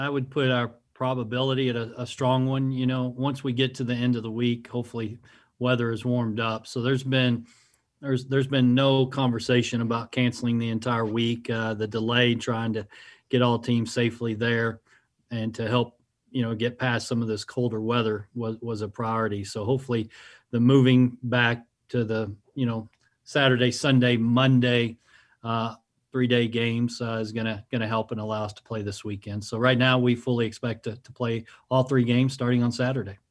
Well our fears were allayed today on the Kirby Hocutt Radio Show. You can click the link for the full show or hop in the clip below for the specifics from the big man himself, Texas Tech Athletics Director Kirby Hocutt.